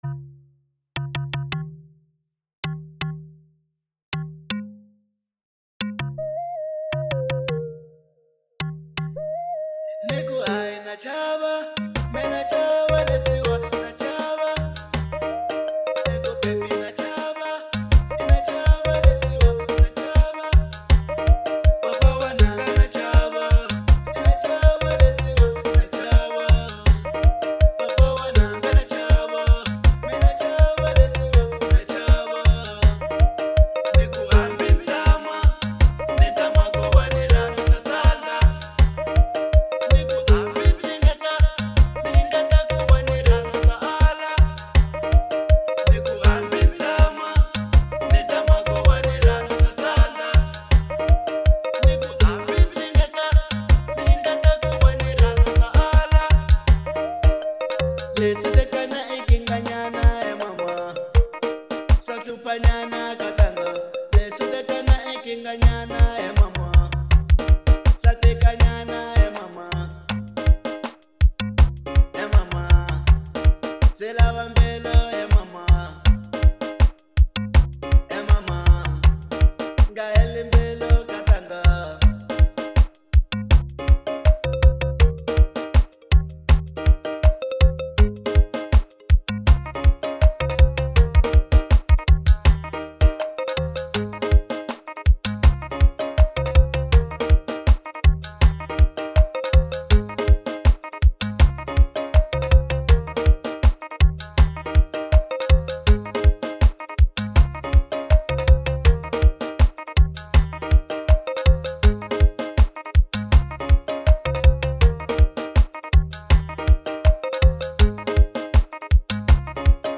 05:04 Genre : Xitsonga Size